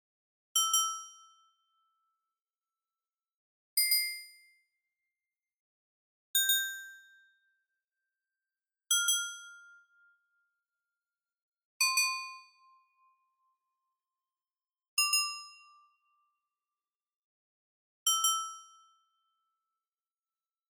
Bells Ringing
10 bell bells cathedral chime church church-bell clang sound effect free sound royalty free Sound Effects